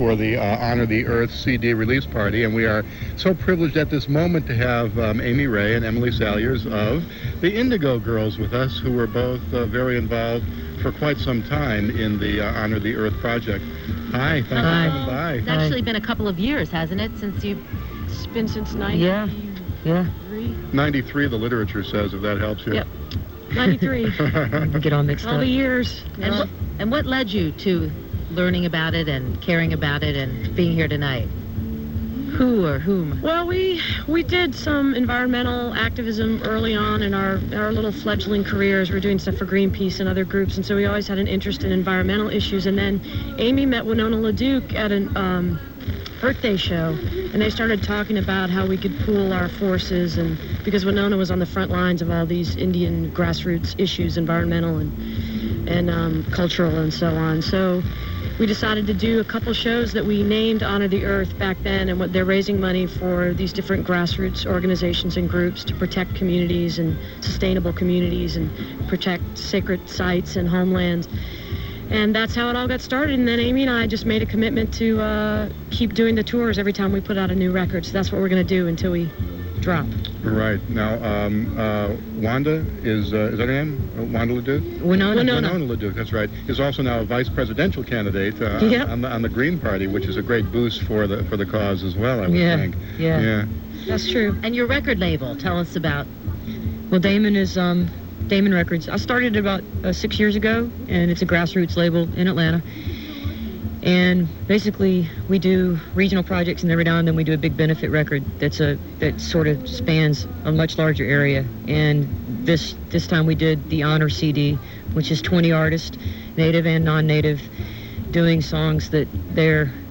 01. interview (3:30)